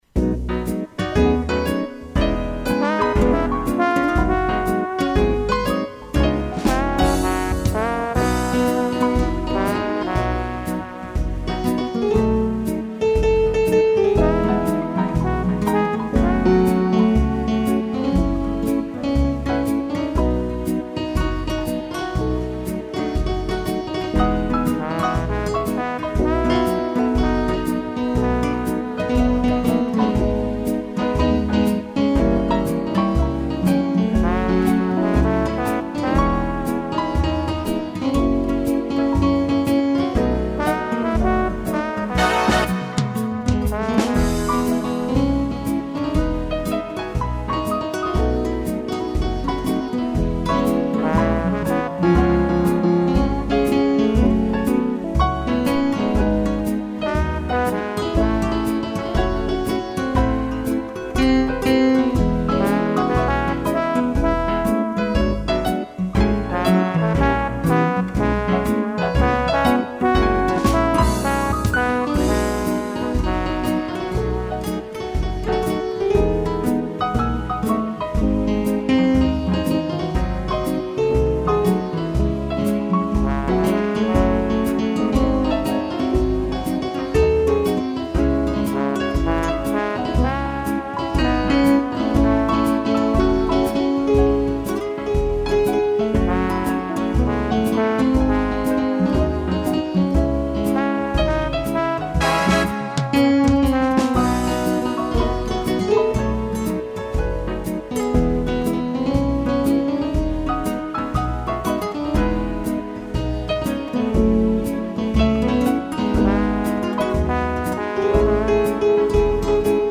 violão
piano e trombone
instrumental